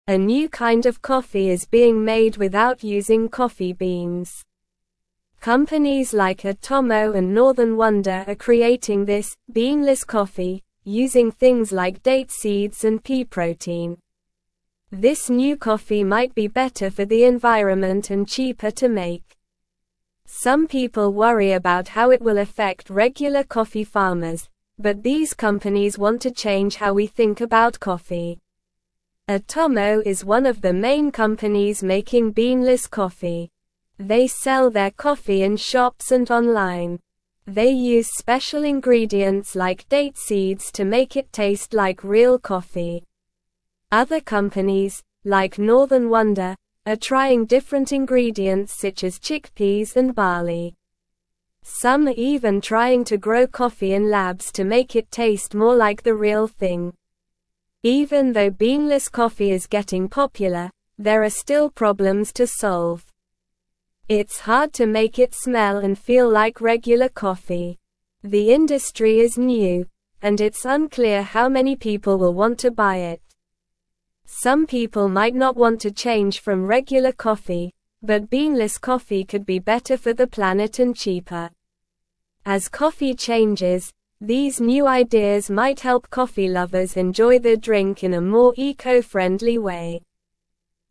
Slow
English-Newsroom-Lower-Intermediate-SLOW-Reading-Beanless-Coffee-A-New-Kind-of-Earth-Friendly-Brew.mp3